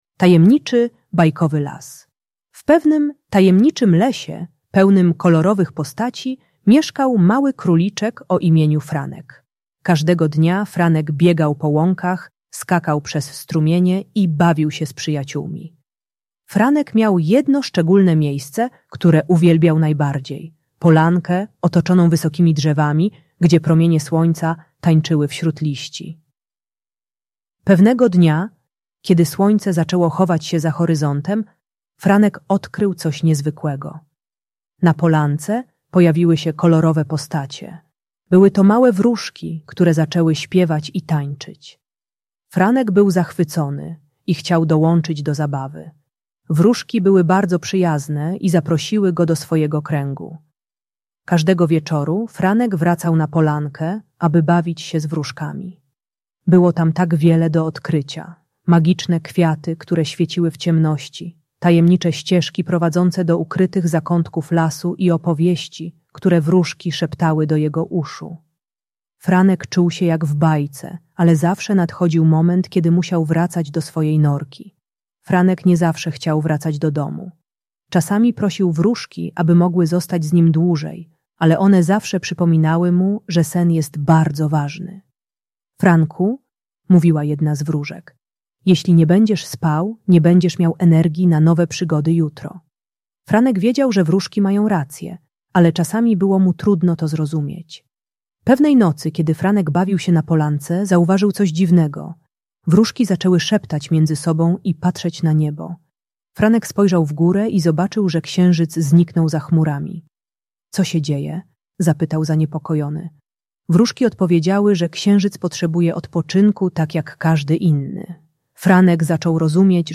Ta bajka dla dziecka które nie chce zasypiać uczy, że sen daje energię na nowe przygody. Spokojne audiobajki usypiające dla przedszkolaków z relaksacyjną narracją o magicznym lesie.